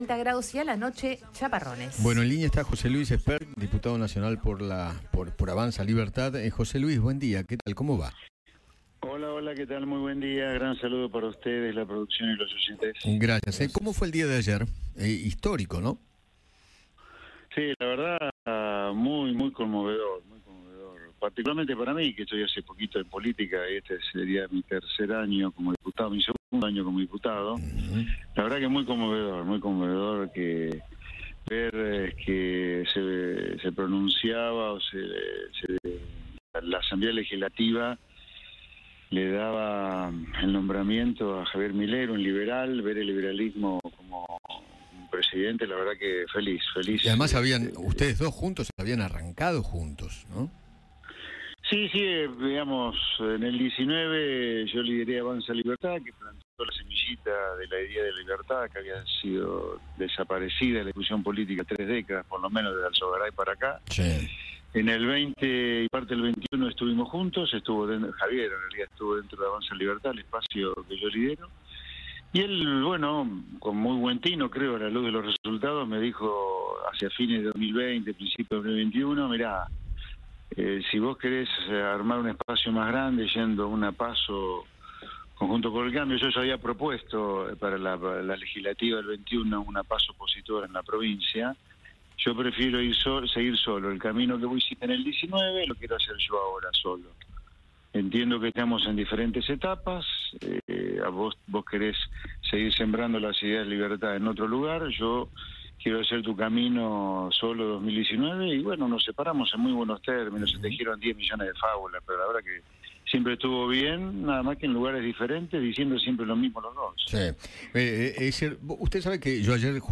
José Luis Espert, presidente del espacio Avanza Libertad, habló con Eduardo Feinmann sobre el encuentro con Javier Milei en la sesión de la Asamblea Legislativa para su nombramiento como mandatario argentino.